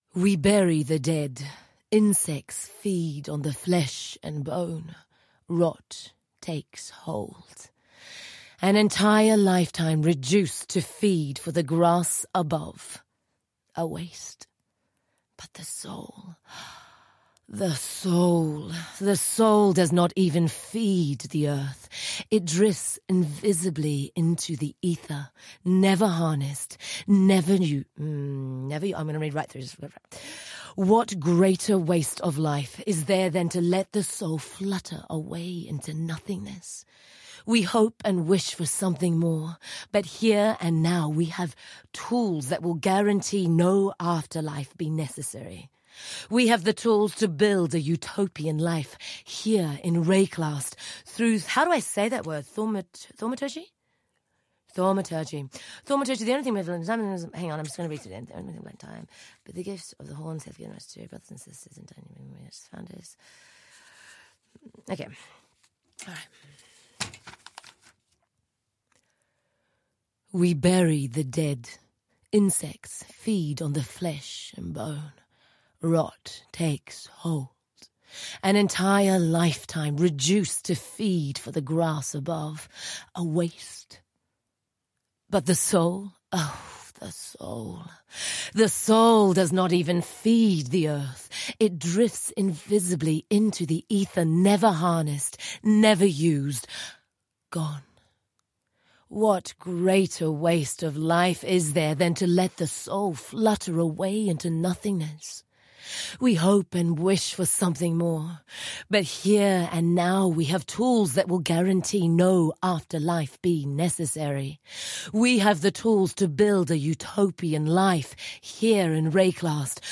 (it's a blooper back from the Betrayal league when the voice actress couldn't say the word thaumaturgy).